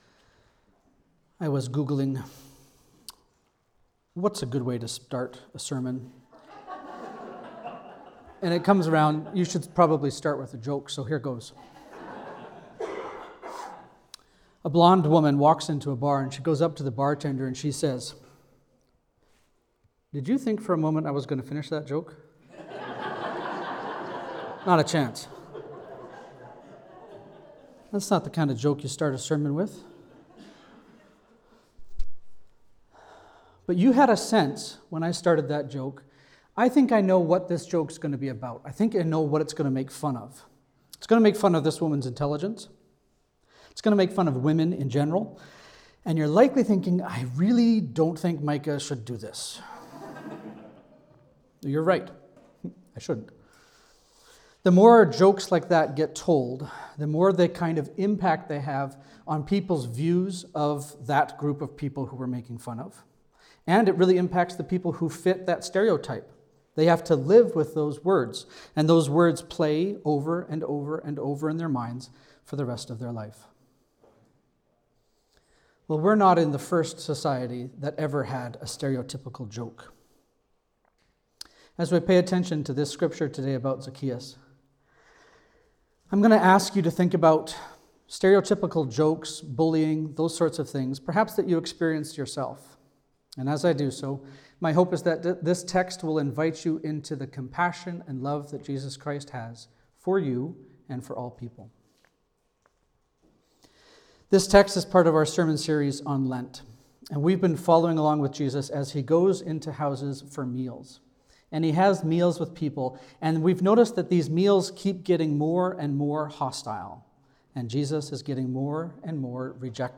Sermons | Living Hope Church